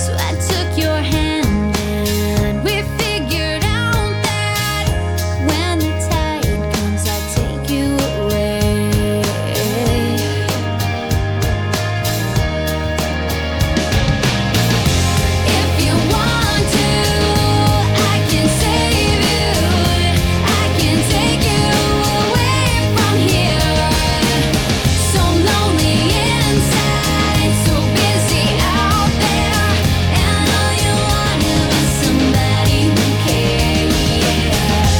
Жанр: Поп / Рок / Альтернатива